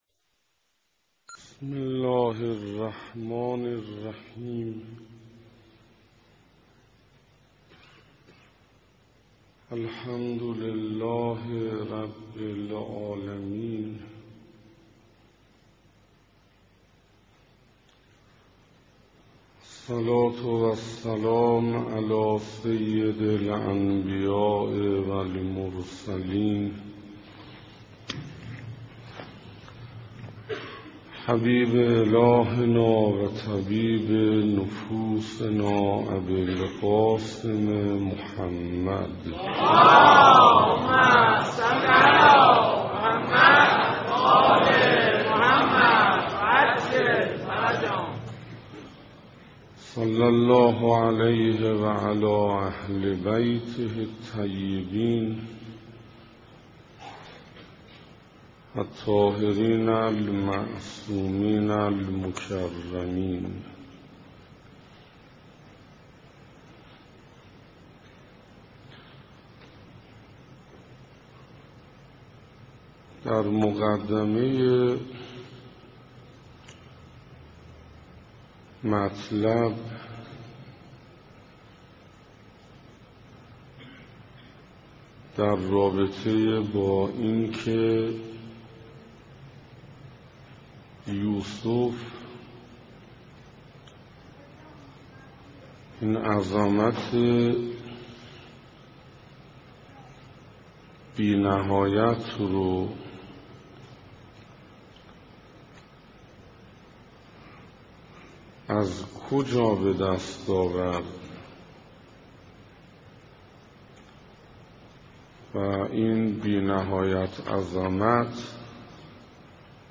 ارزشهاي يوسف در برابر زليخا - سخنراني بيست و چهارم - رمضان 1427 - حسینیه همدانی‌ها